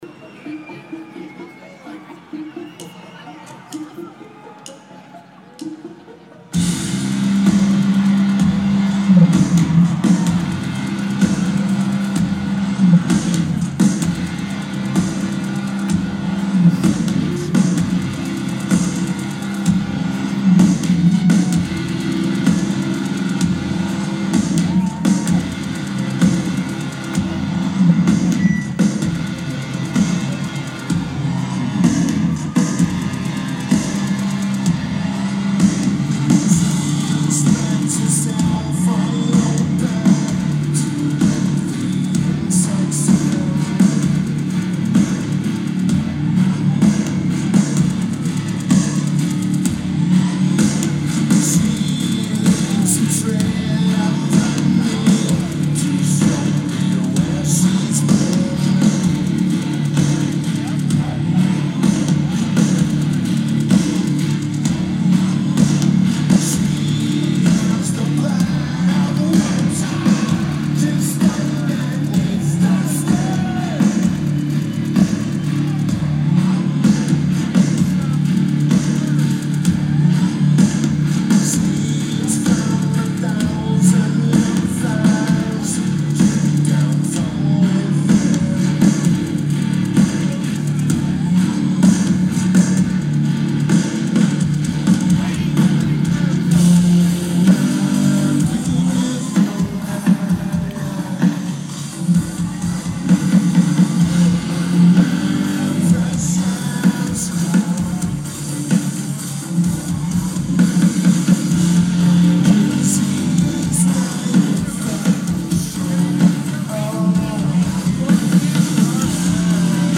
XFINITY Center
Lineage: Audio - AUD (Sony PCM-M10 + Internal Mics)